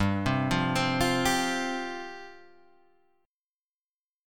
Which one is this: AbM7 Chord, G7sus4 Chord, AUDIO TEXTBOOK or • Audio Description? G7sus4 Chord